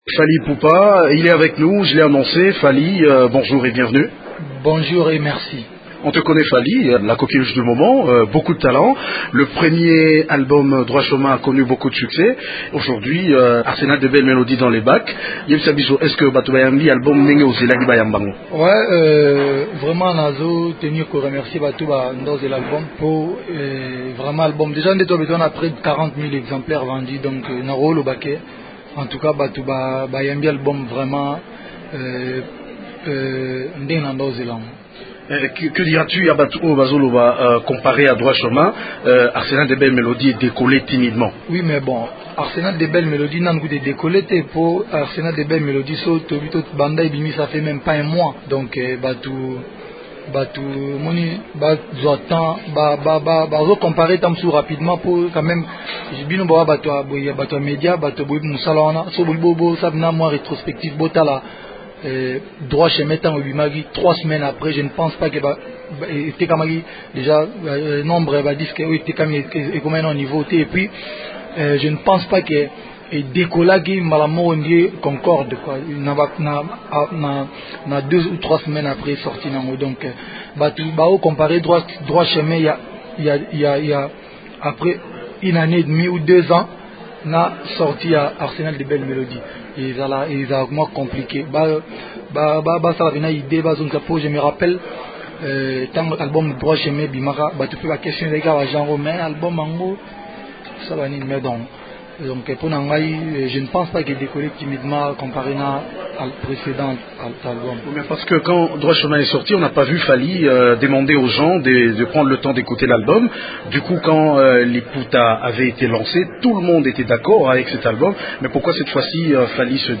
Il est au micro